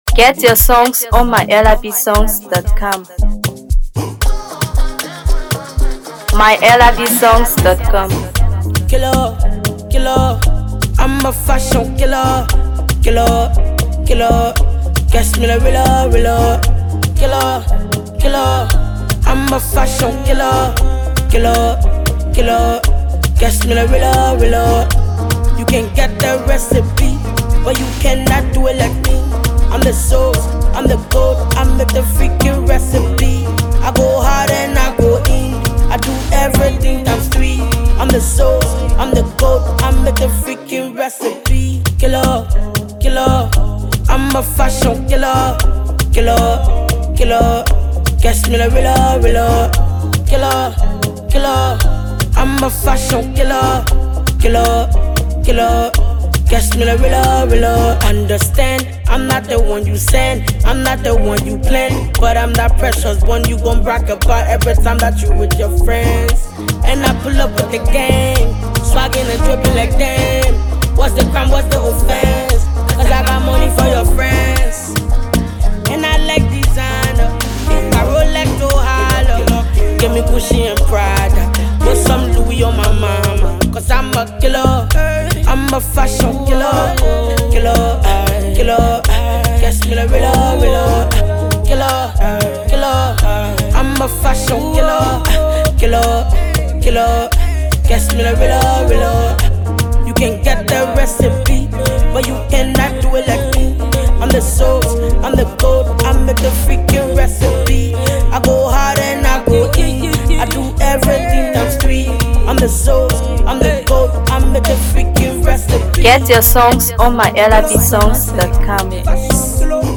Known for her unique blend of Afrobeat, Dancehall, and Hipco